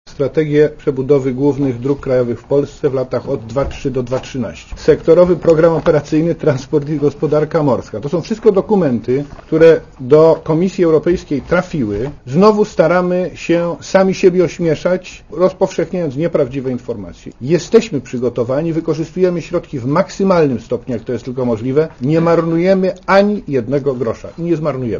Dla Radia Zet mówi wicepremier Marek Pol (97 KB)